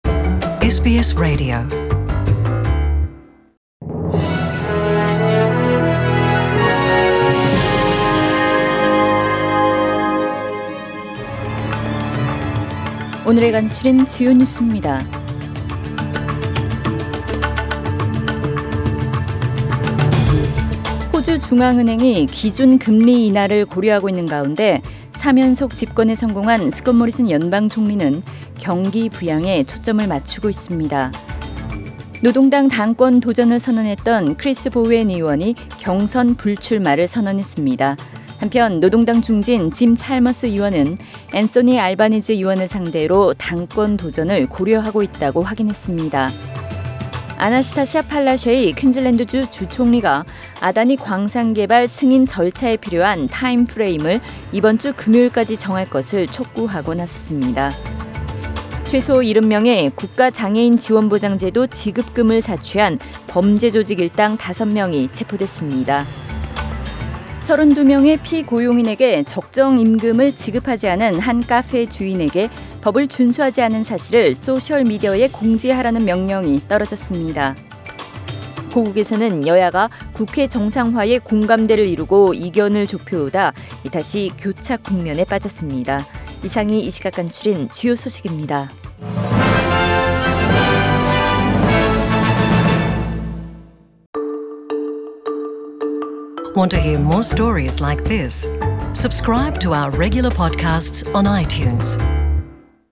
2019년 5월 22일 수요일 저녁의 SBS Radio 한국어 뉴스 간추린 주요 소식을 팟 캐스트를 통해 접하시기 바랍니다.